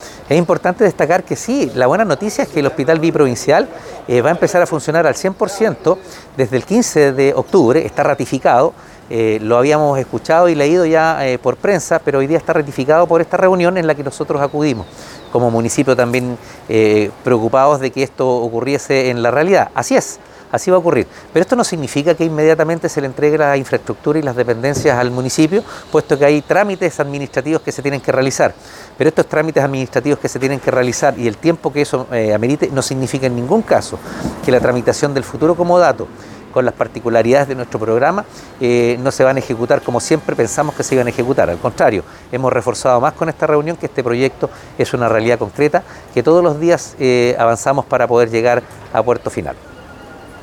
cuna-alcalde-calderon-02-1.mp3